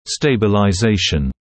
[ˌsteɪbəlaɪ’zeɪʃn] [ˌстэйбэлай’зэйшн] стабилизация (British English stabilisation)
stabilization.mp3